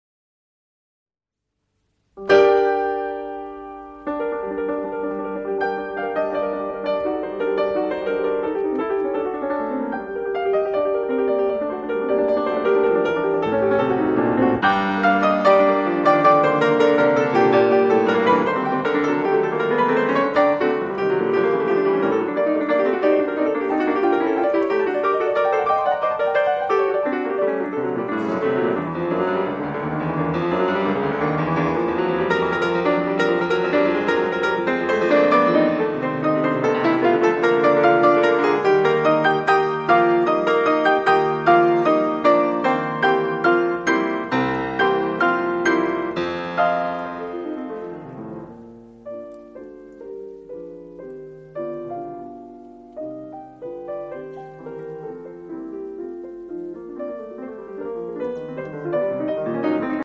Piano Music